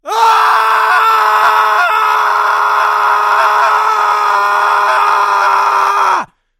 Ликующий крик: наступил 2023 год